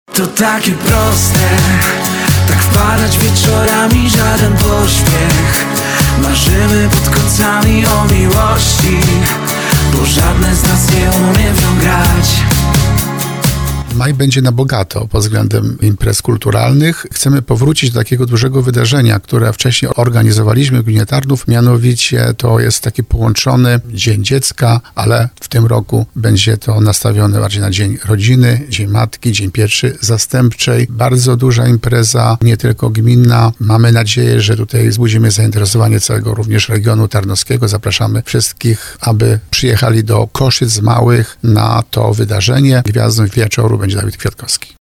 -Na miejscowym boisku będzie czekał piknik z atrakcjami dla całych rodzin, grami i zabawami dla najmłodszych oraz, na zakończenie, koncertem gwiazdy – zapowiadał w RDN Małopolska wójt Grzegorz Kozioł.